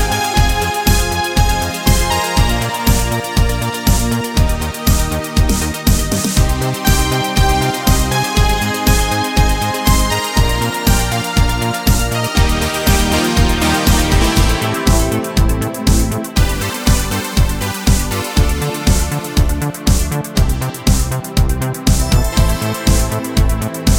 no Backing Vocals Disco 3:34 Buy £1.50